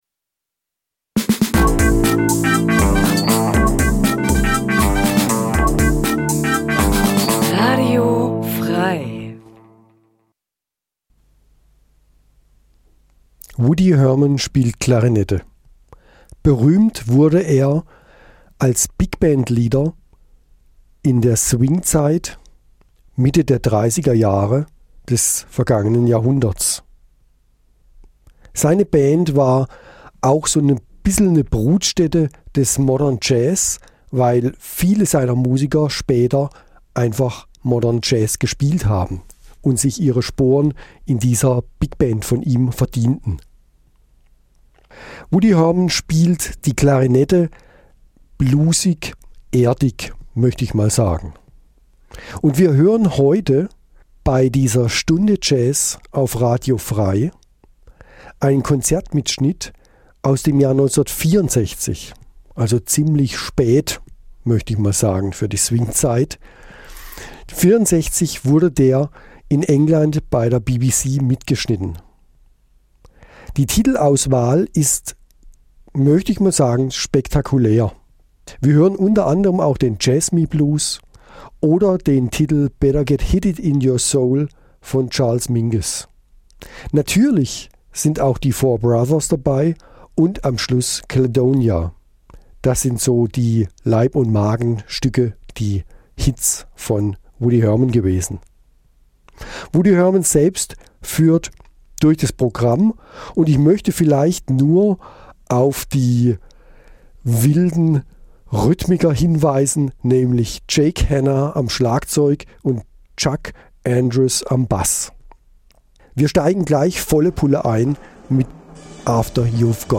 Eine Stunde Jazz Dein Browser kann kein HTML5-Audio.